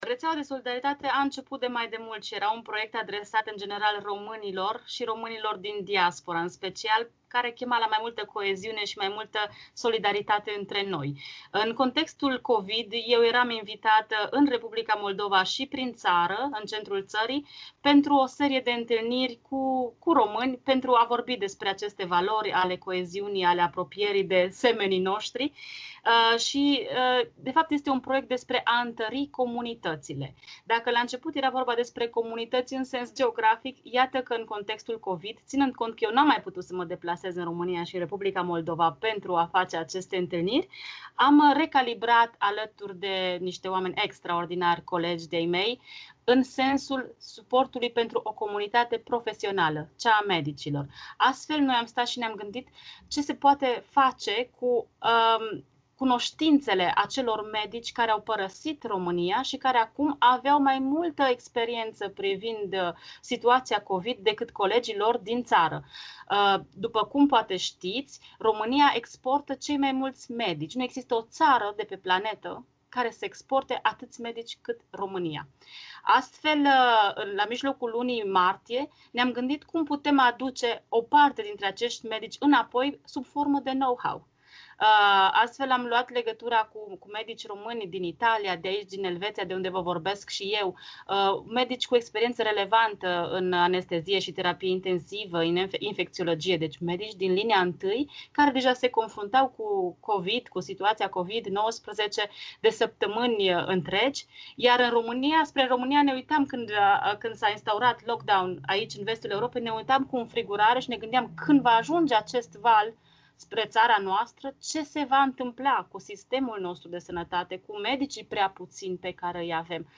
Interviu cu o expertă din România stabilită în Elveția care coordonează o rețea de solidaritate formată din medici români din întreaga lume.